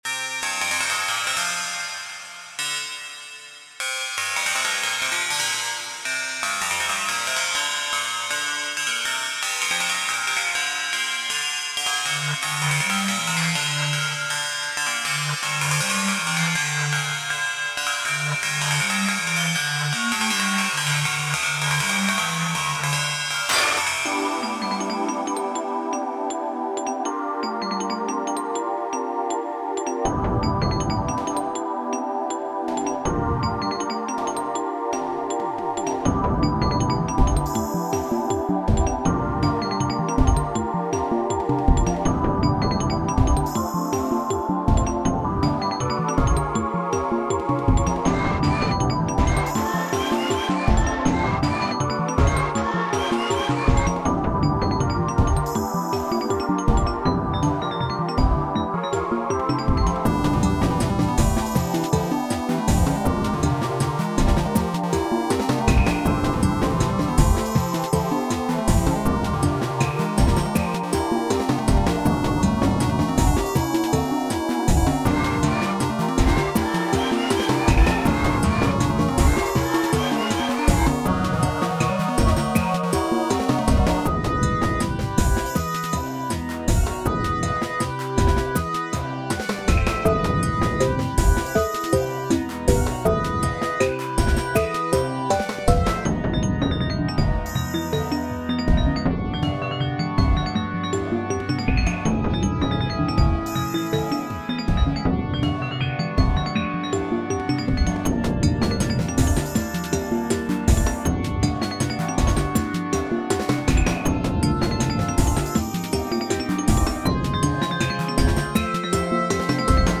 Endgame bosses. Exciting, evocative, a little annoying, but gives the sensation of hurtling backwards through time... think if the Chamber of Light where you talk to the Sages in Ocarina of Time hosted battles....
Music / Game Music